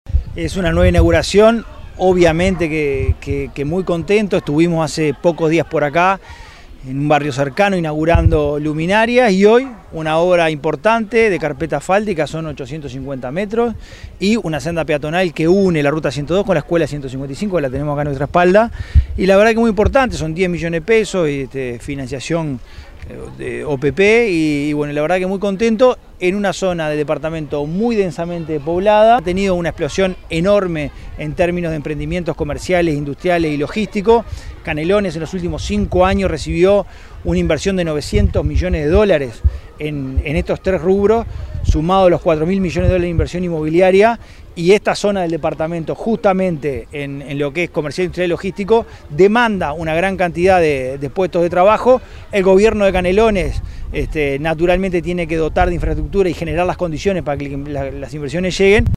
El miércoles 12 de abril en Colonia Nicolich se llevó a cabo la inauguración de obras de pavimentación. La ceremonia se realizó frente a la escuela N° 155 y participó el Secretario General de la Intendencia de Canelones, Dr. Esc. Francisco Legnani, el Alcalde del Municipio de Nicolich – Ciudad Gral. Líber Seregni, Líber Moreno, entre otras autoridades, vecinas y vecinos de la localidad.